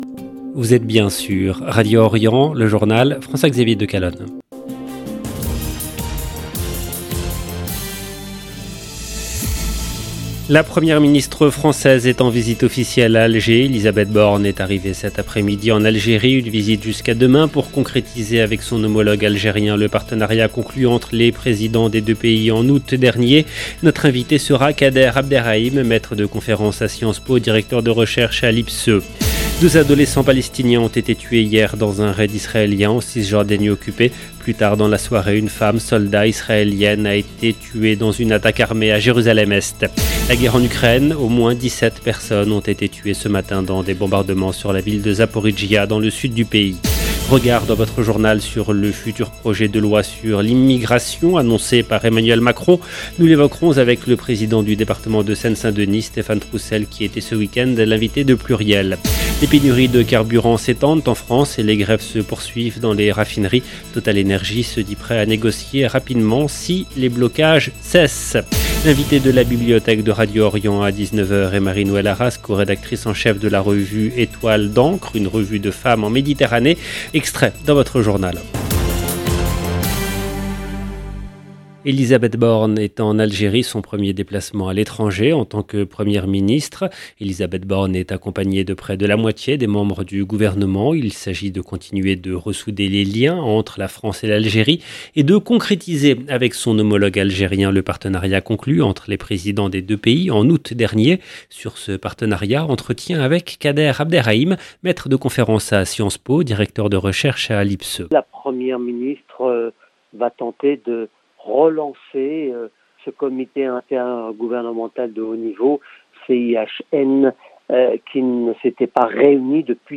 EDITION DU JOURNAL DU SOIR EN LANGUE FRANCAISE DU 9/10/2022